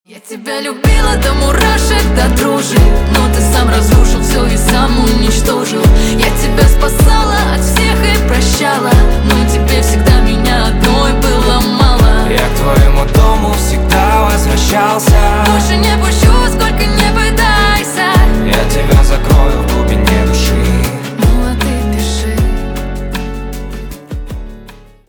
на русском грустные на бывшего